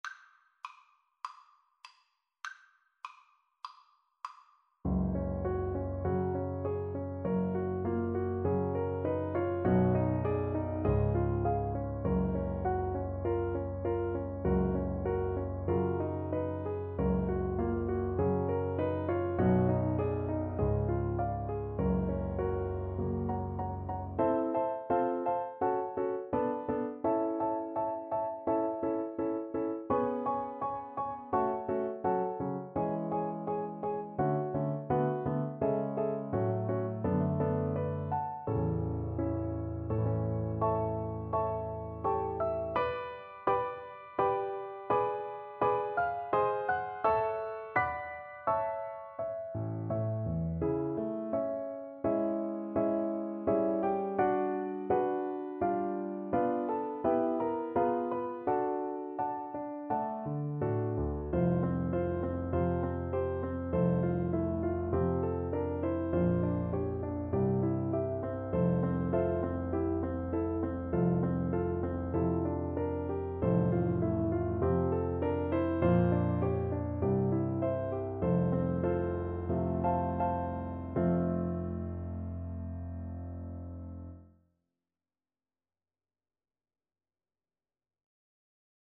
Moderato
4/4 (View more 4/4 Music)